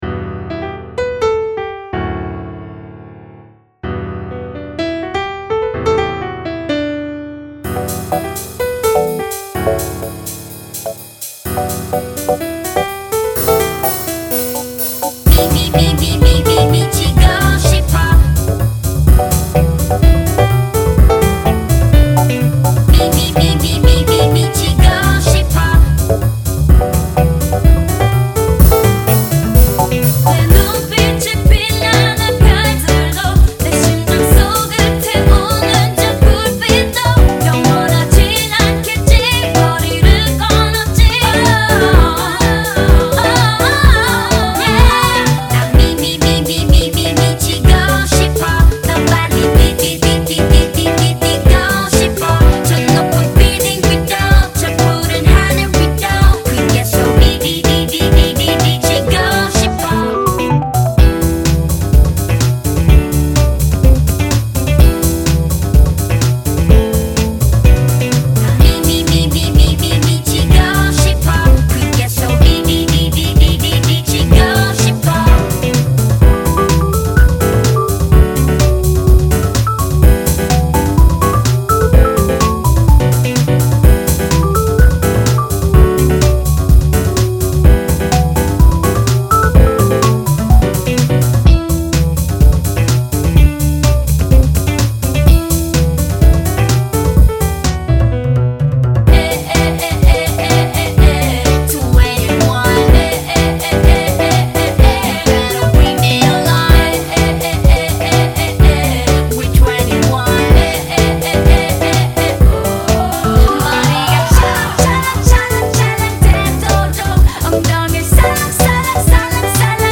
재즈 많이 들어본것도 아니고
맛만 살짝 내봤습니다.